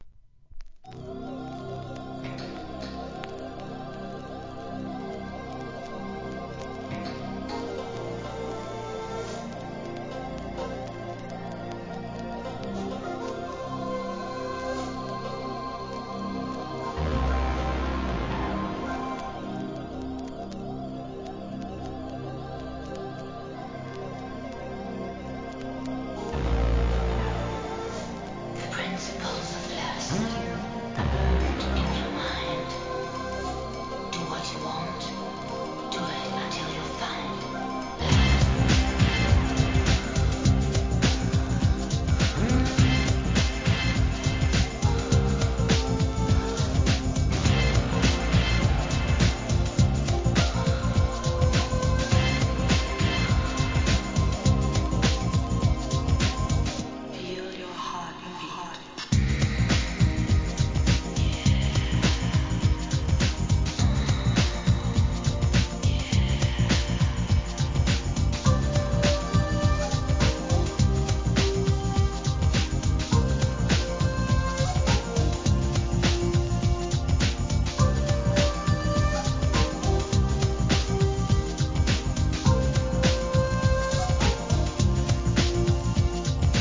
神秘的で浮遊感のあるシンセや笛のメロディーを用いたアンビエント、グラウンド・ビート！